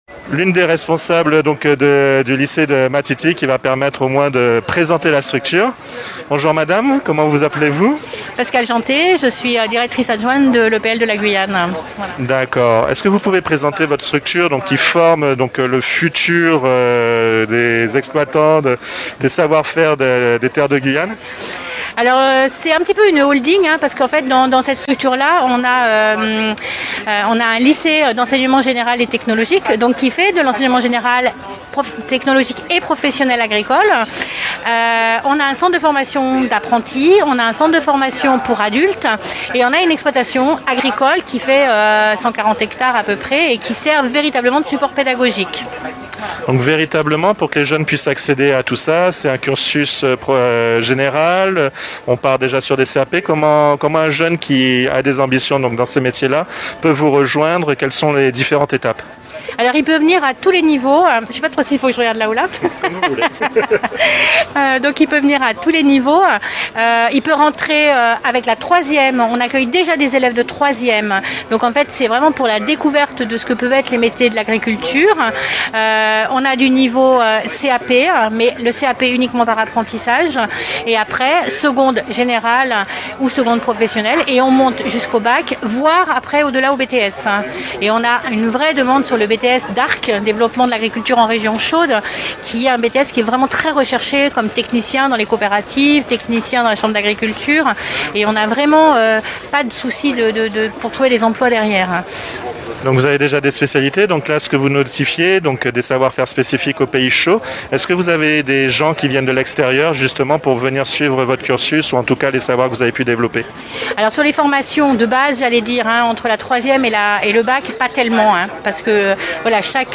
Retour sur la 1ere édition du e-salon de l'interprofession de l'élevage et de la viande en Guyane, (INTERVIG) où Radio Mayouri Campus La radio du savoir a donné la parole aux exposants.